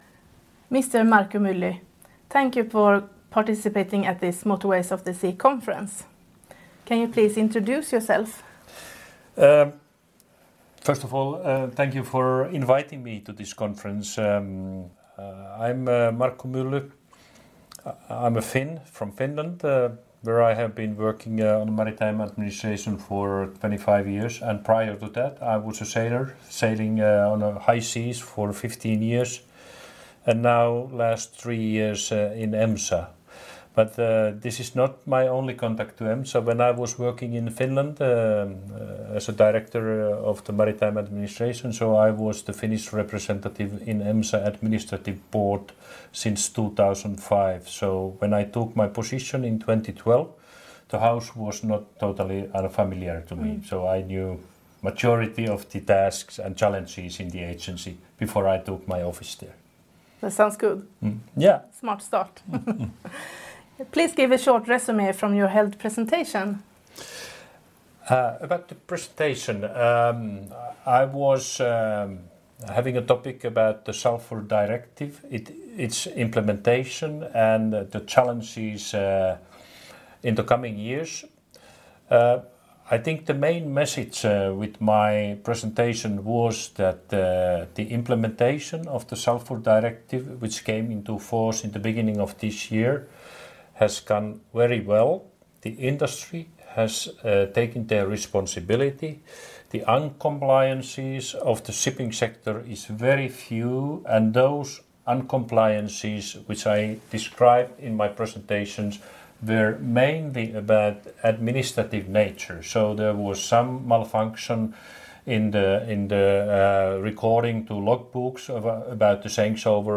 Listen to the interview with Markku Mylly, EMSA or read the transcript below.